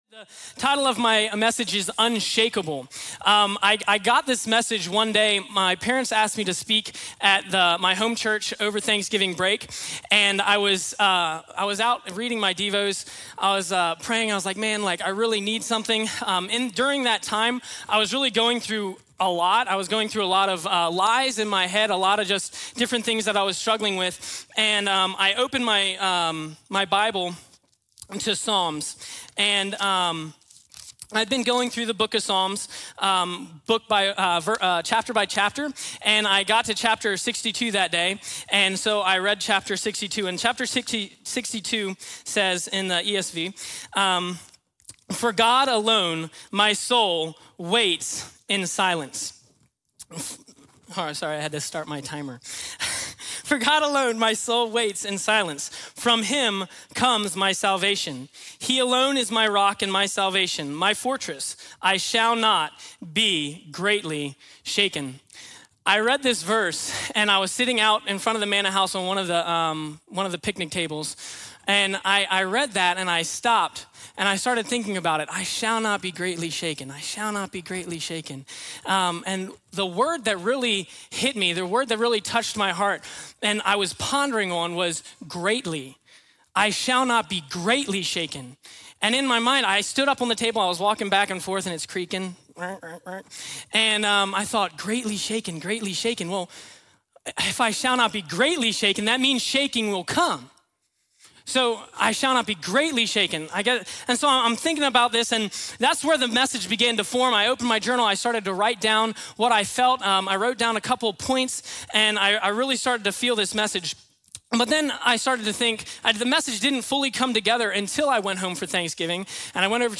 Unshakeable | Times Square Church Sermons